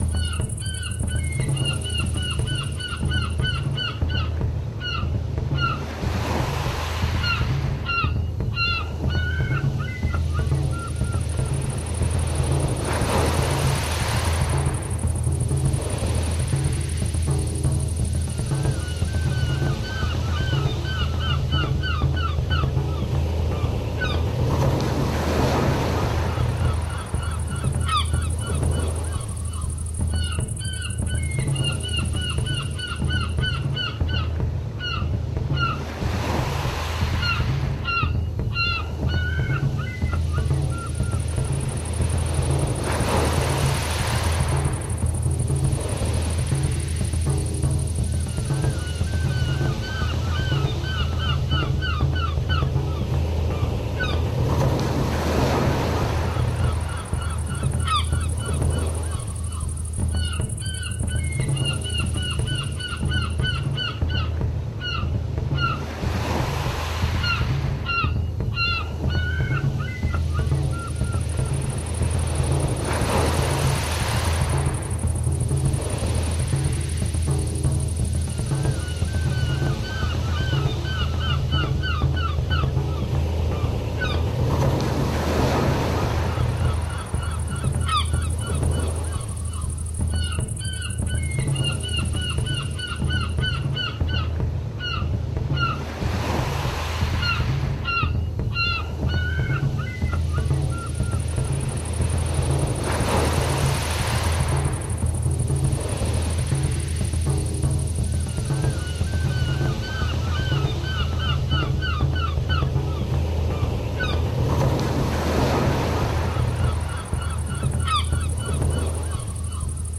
nature.mp3